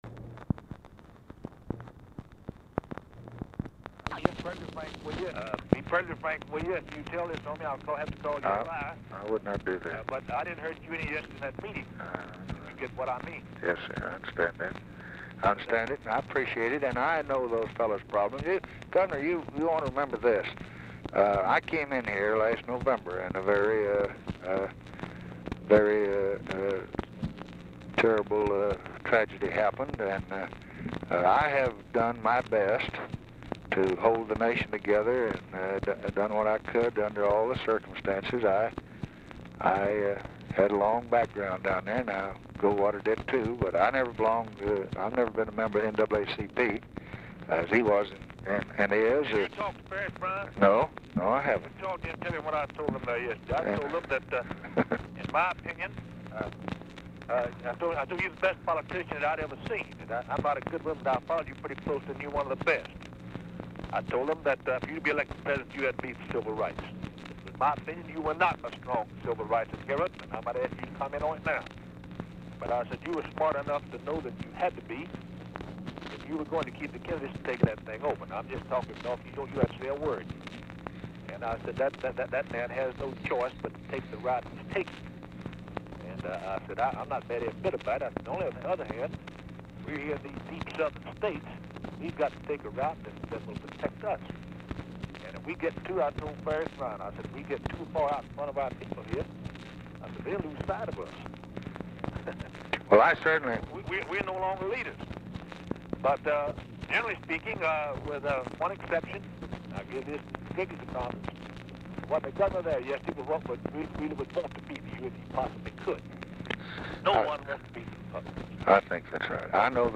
Telephone conversation # 4968, sound recording, LBJ and JOHN MCKEITHEN, 8/15/1964, 3:15PM
Format Dictation belt